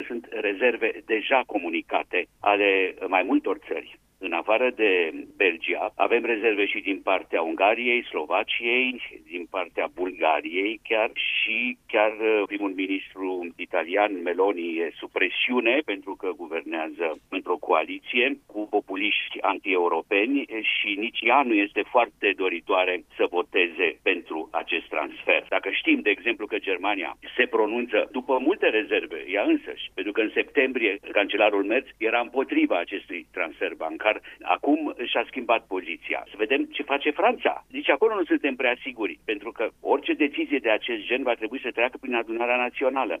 Nu este însă singurul stat membru care are îndoieli, după cum a afirmat la Radio România Actualităţi fostul ministru de externe, Emil Hurezeanu: